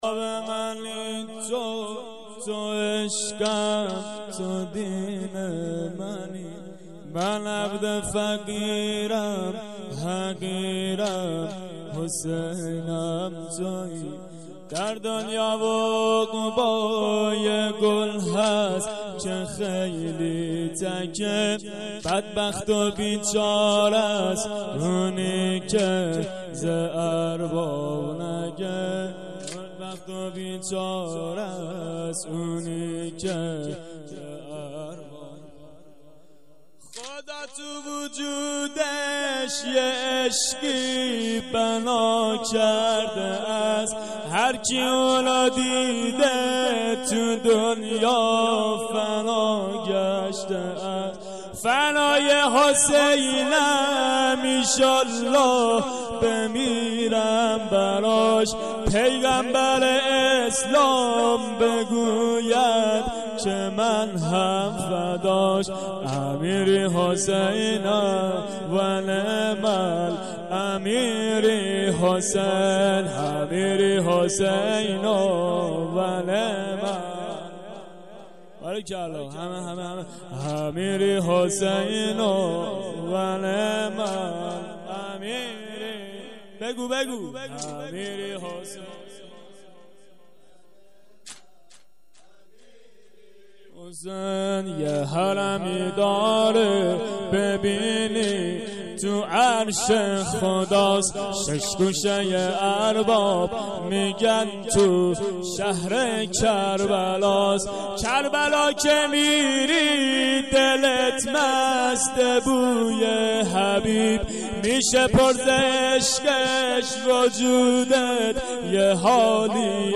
واحد/ارباب منی تو تو عشم تو دین منی